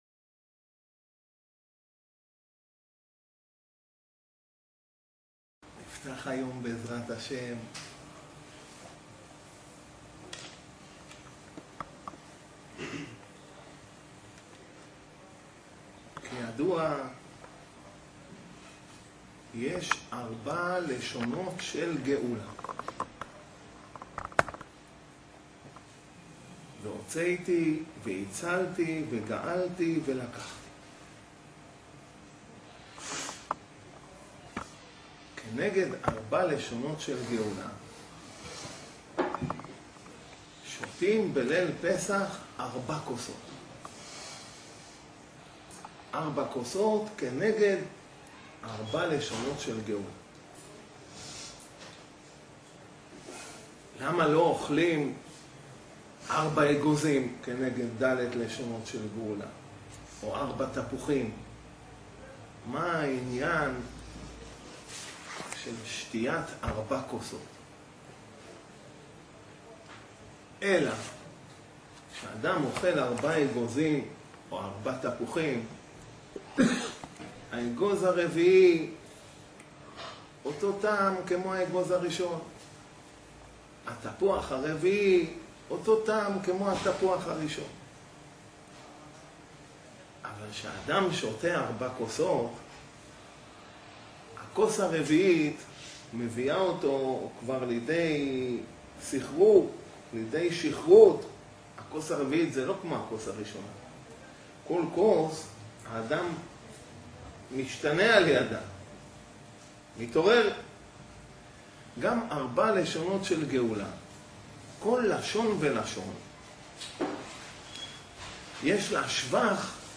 הדרשה נמסרה בישיבת מתימן יבוא ג אייר תשסח. עוד בדרשה, למה אוכלים ביצה בפסח, למה כאב לרבי יהודה ברא הראש עד עצרת. מי היו גיבורי השואה, למה דופקים עם פטישים על הראש, למה פעם היו גדולי ישראל שחגגו את יום זה.